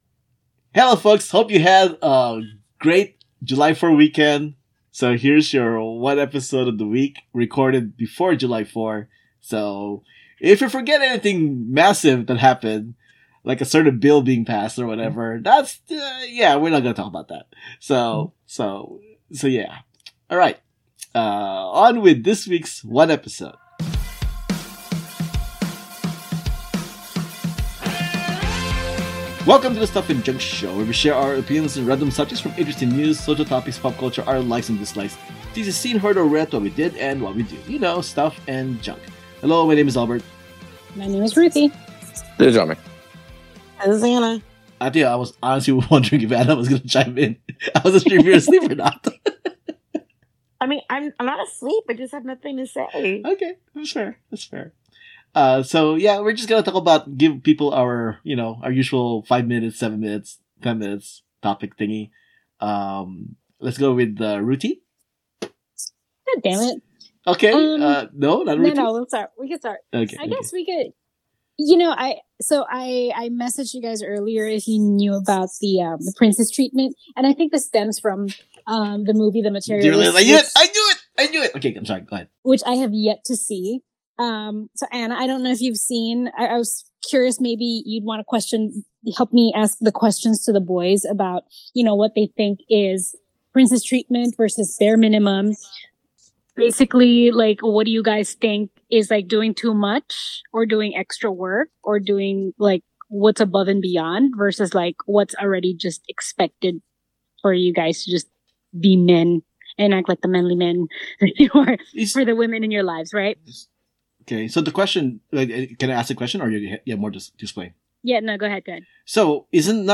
Bare Minimum. scratchy sounds.